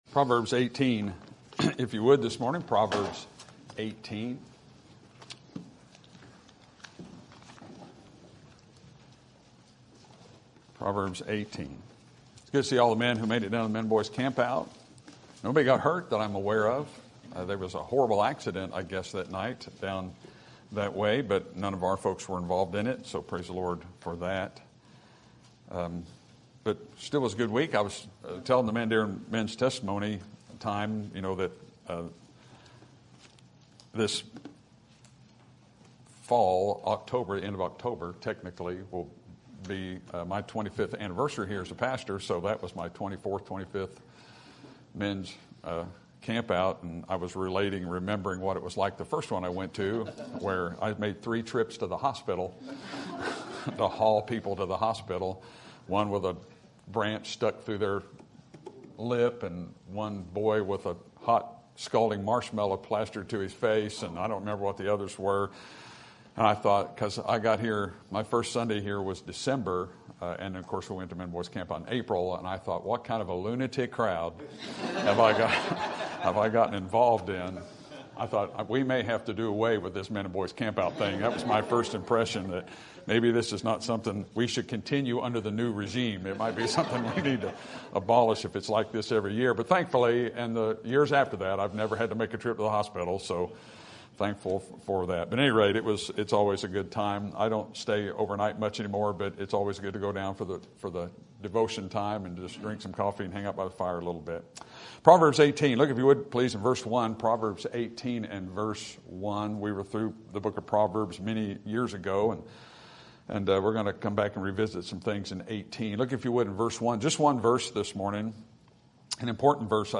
Sermon Topic: General Sermon Type: Service Sermon Audio: Sermon download: Download (23.93 MB) Sermon Tags: Proverbs Service Gifts Ministry